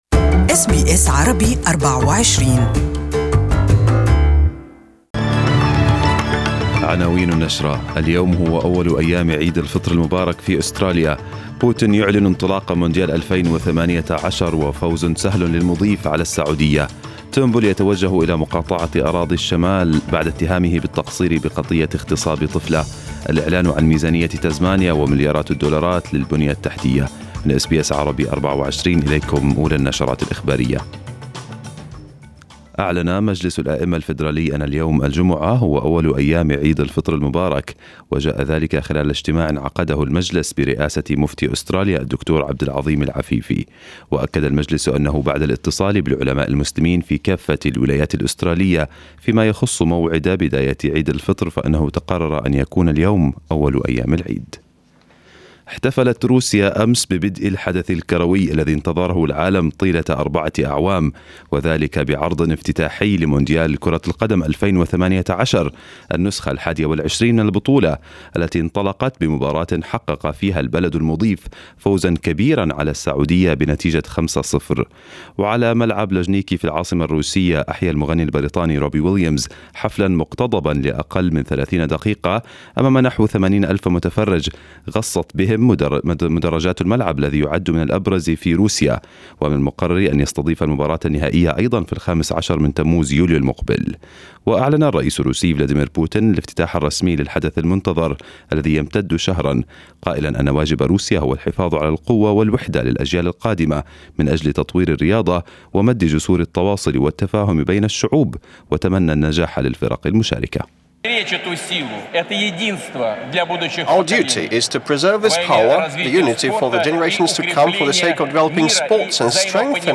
Arabic News Bulletin 15/06/2018